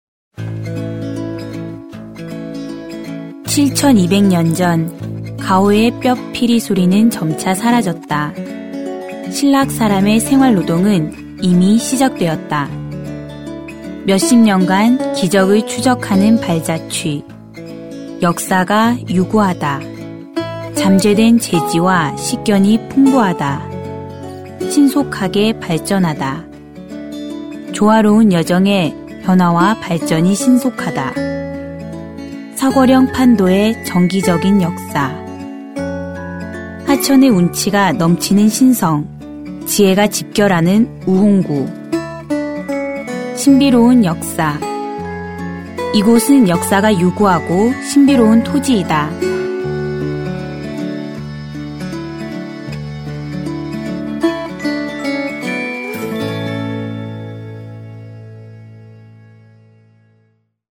外语配音：俄语语配音 日语配音 韩语配音 法语配音 德语配音 西班牙语配音和葡萄牙语配音员及其他小语种配音演员